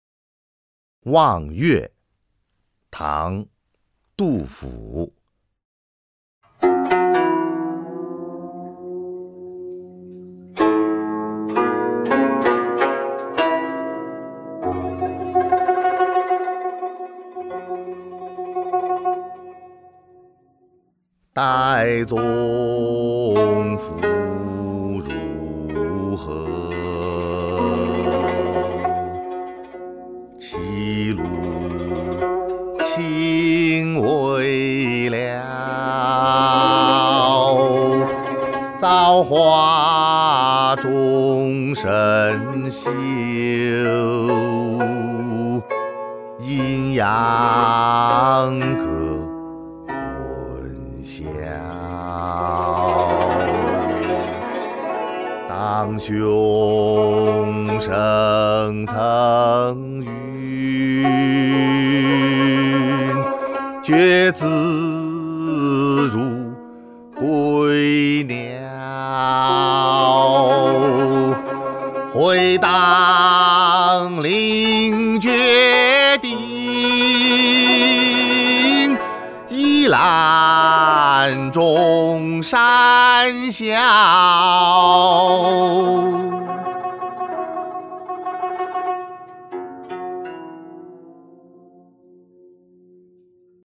［唐］杜甫 《望岳》 （吟咏）